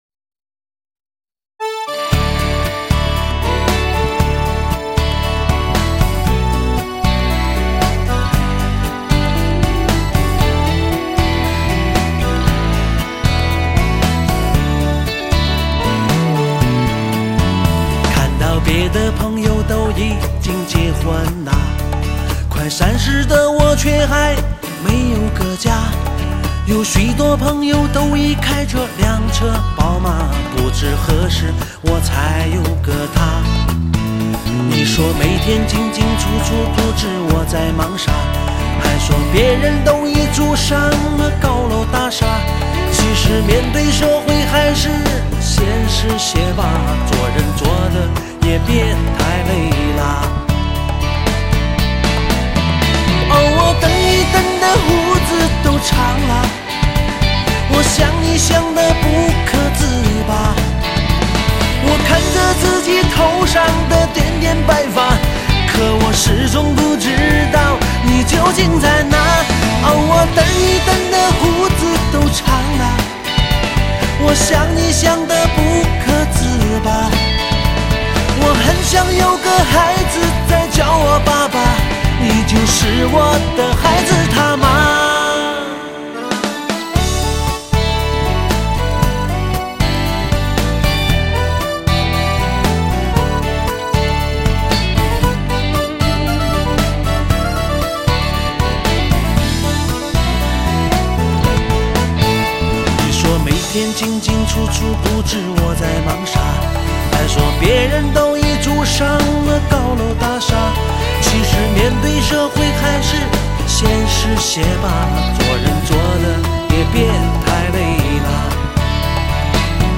DJ版